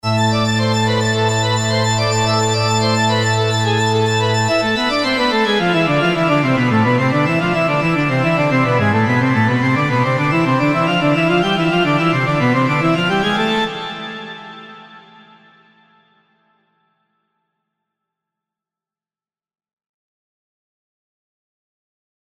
＜デモサウンド＞
今回作成したパイプオルガン音色を使用したフレーズ例です。
リバーブやディレイは本体内蔵エフェクトのみ使用しています。
今回は2個のフィルターをシリアル接続状態で使用しています。
パイプオルガンには空間系エフェクトが最適ですので、セオリー通りリバーブを使用し、割と深めにタップリと残響を足しています。
また、ディレイを併用して少し立体的なサウンドに仕上がるようにしてみました。